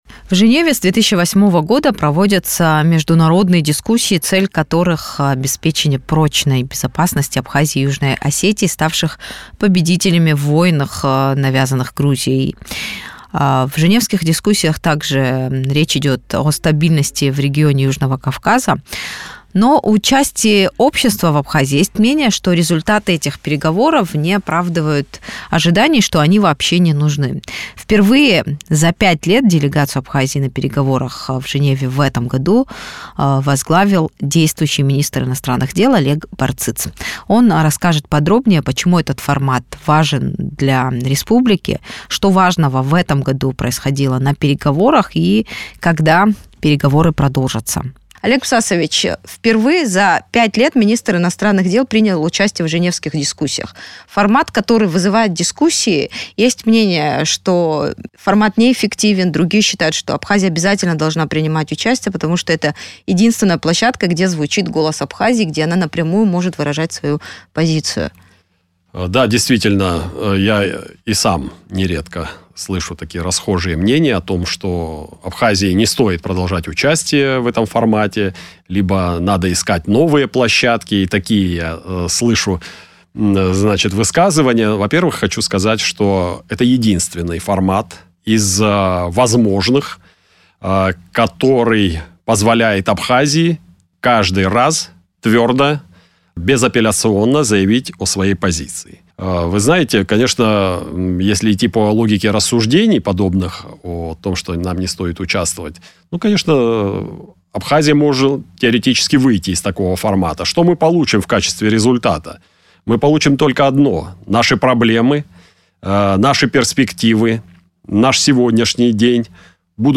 Гости
Олег Барциц, министр иностранных дел Абхазии